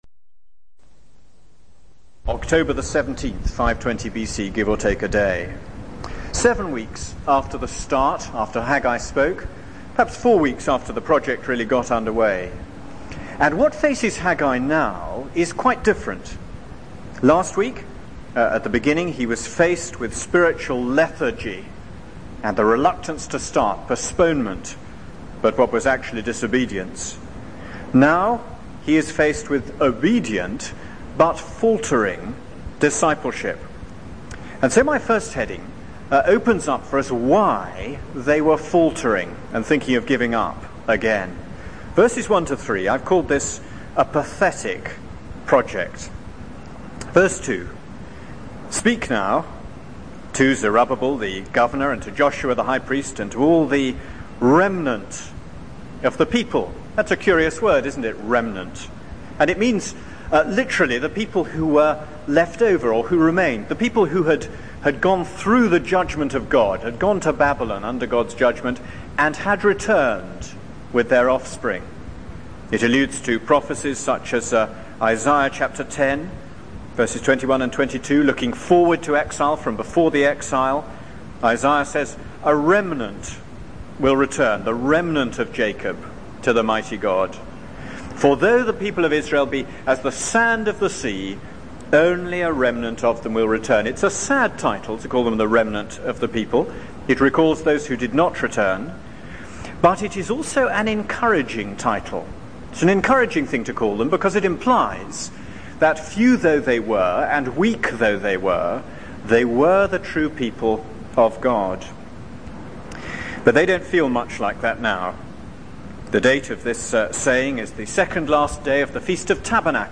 This is a sermon on Haggai 2:1-9.